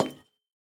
Minecraft Version Minecraft Version 1.21.5 Latest Release | Latest Snapshot 1.21.5 / assets / minecraft / sounds / block / copper_bulb / step4.ogg Compare With Compare With Latest Release | Latest Snapshot